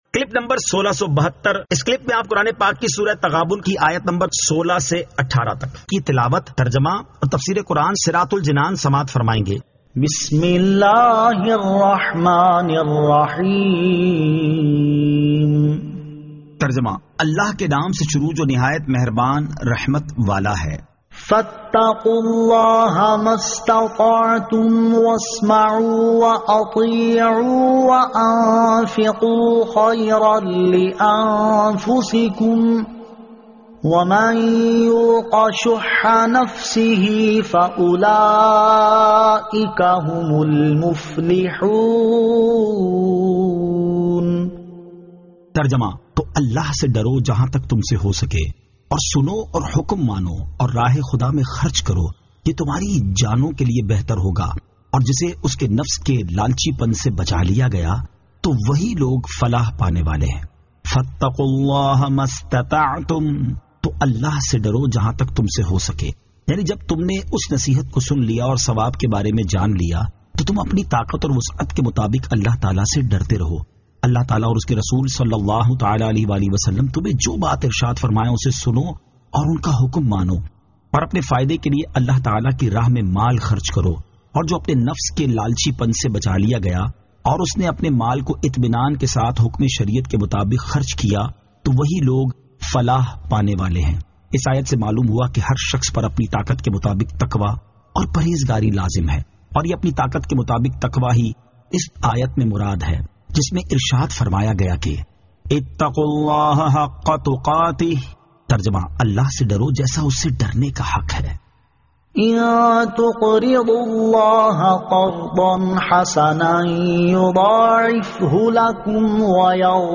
Surah At-Taghabun 16 To 18 Tilawat , Tarjama , Tafseer